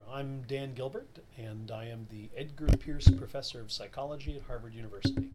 Here is Dr. Gilbert introducing himself:
gilbert_sayshello_intro.mp3